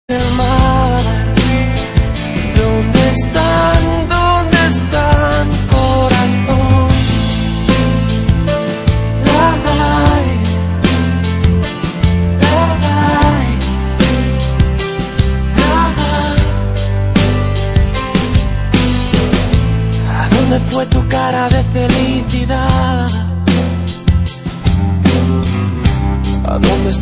• Latin Ringtones